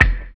LO FI 4 BD.wav